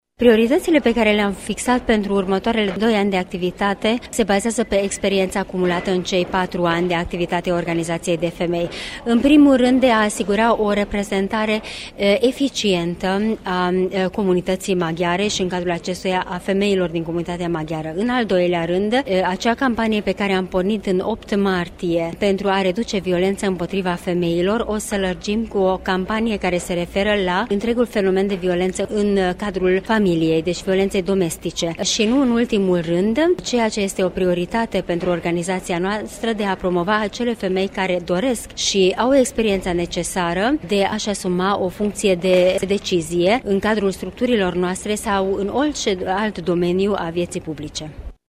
Astăzi s-a desfășurat la Tg.Mureș, în sala mică a Palatului Culturii, ședința organizației.
Iată ce a declarat pentru Radio Tg.Mureş după ce a fost realeasă în funcție Bíró Rozalia legat de obiectivele si priorităţile organizației: